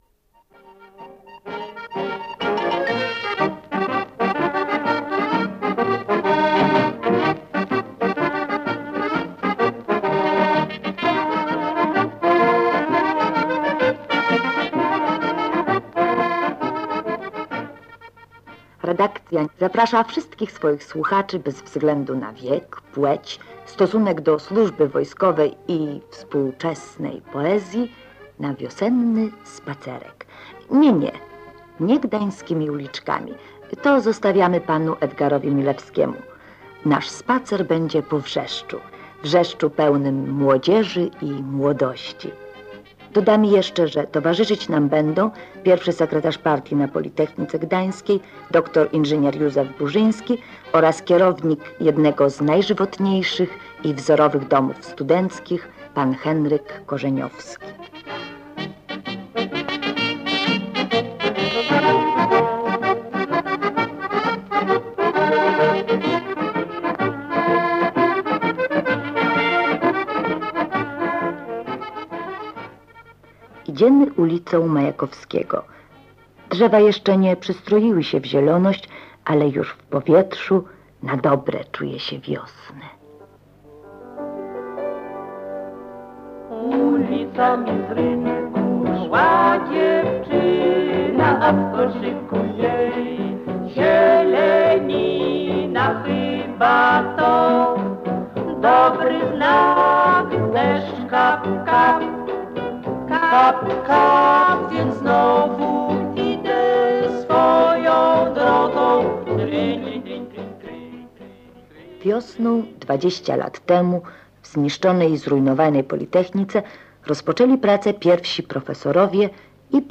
Audycja